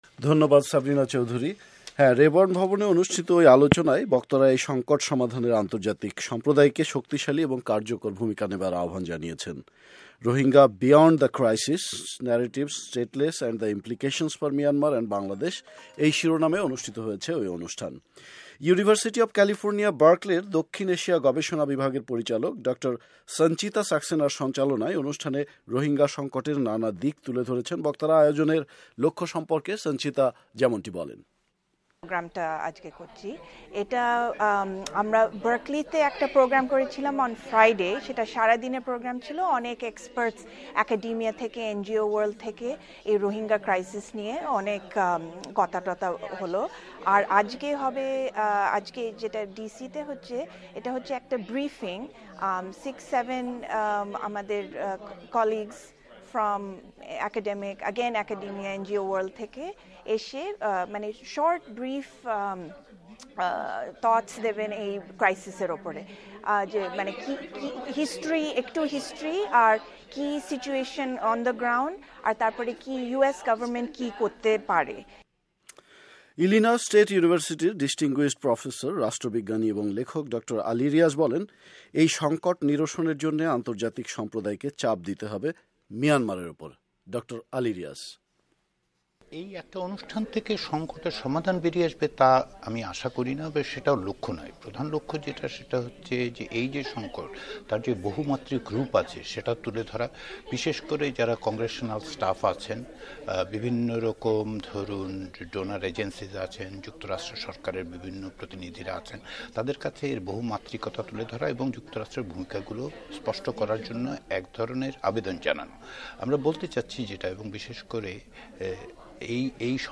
রোহিঙ্গা সংকট সমাধানে আন্তর্জাতিক সম্প্রদায়কে আরো শক্ত ও দায়িত্বশীল ভুমিকা পালনের আহবান জানালেন ওয়াশিংটনের রেবর্ন কংগ্রেস ভবনে অনুষ্ঠিত এক আলোচনা সভায় বক্তারা।